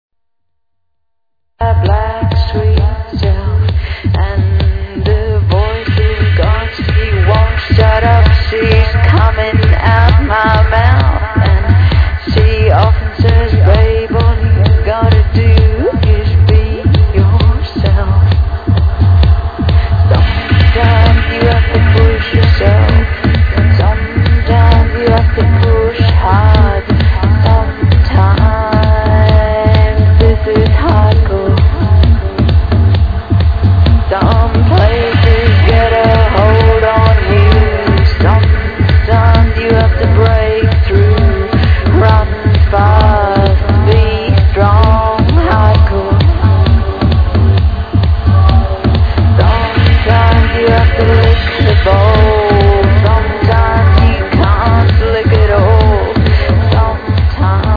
TranceAddict Forums > Tracks Section > Unknown Tracks > ID Vocaly Tune!
Plz ID This Vocaly Tune